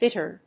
音節bit・tern 発音記号・読み方
/bíṭɚn(米国英語), bítən(英国英語)/